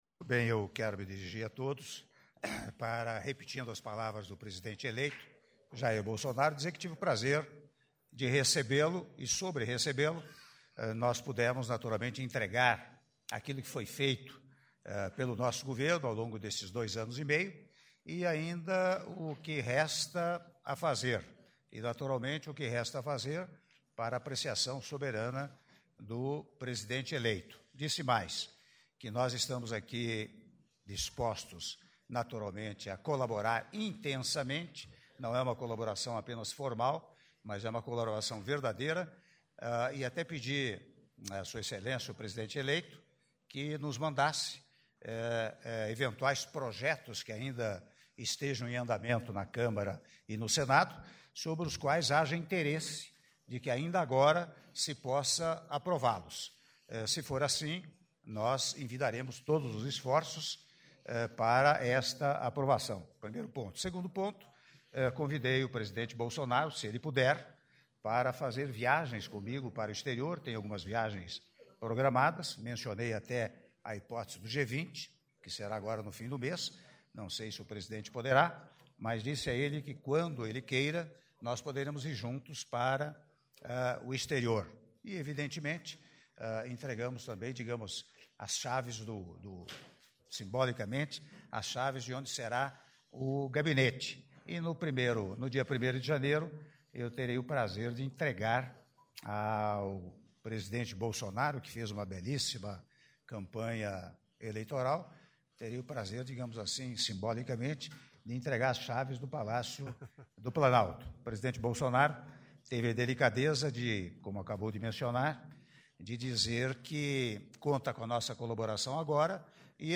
Áudio da Declaração à imprensa do Presidente da República, Michel Temer, após reunião com o Presidente da República eleito, Jair Bolsonaro - (02min47s) — Biblioteca